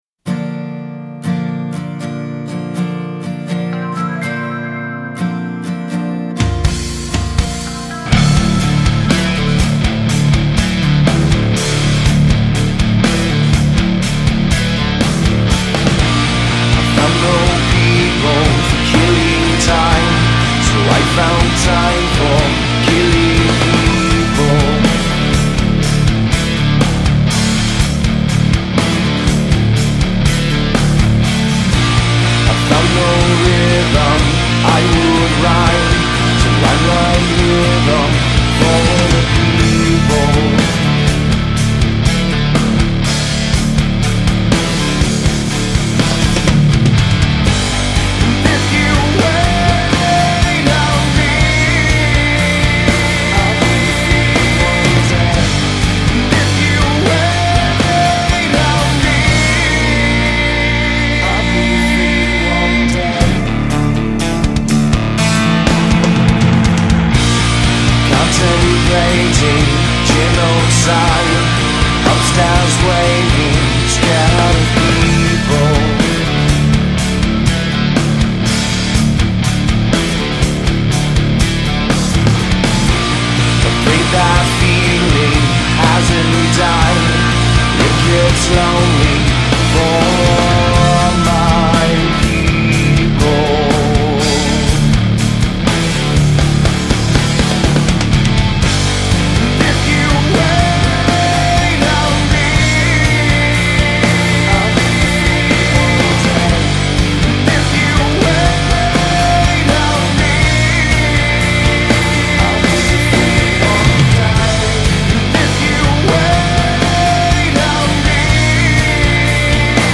Жанр: alternative